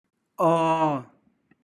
/oor/